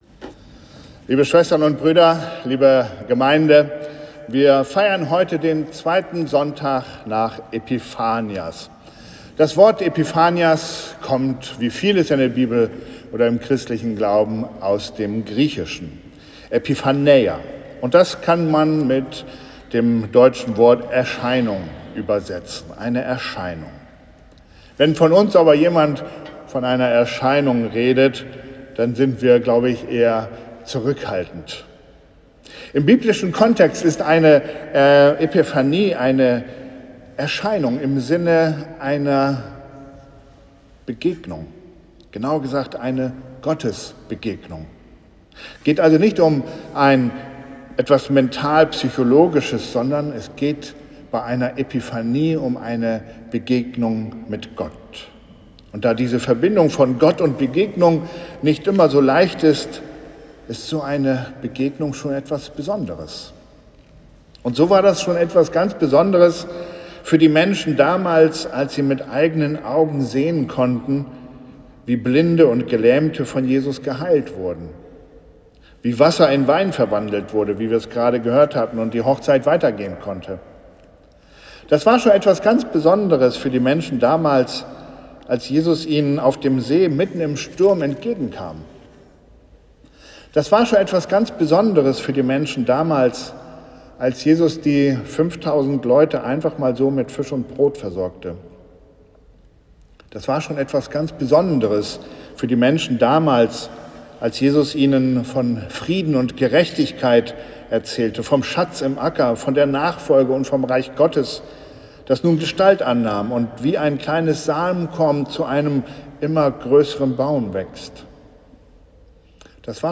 Gottesdienst am 2. Sonntag nach Epiphanias